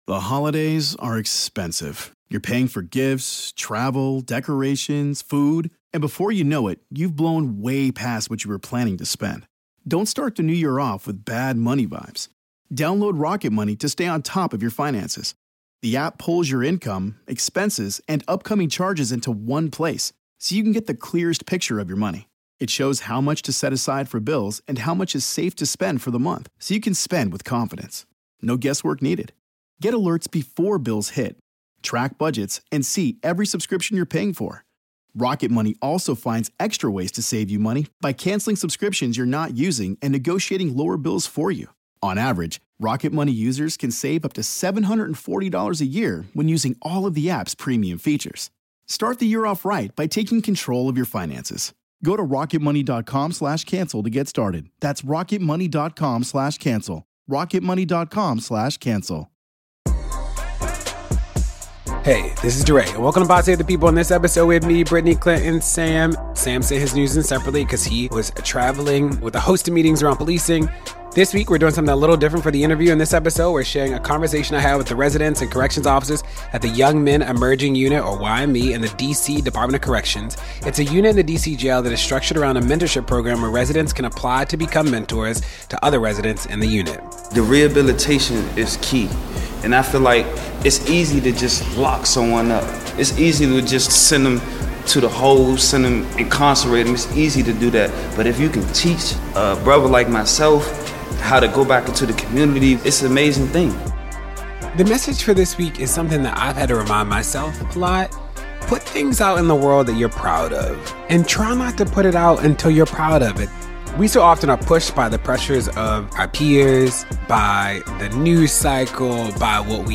DeRay talks to residents and corrections officers at the Young Men Emerging unit in the D.C. Department of Corrections about policing.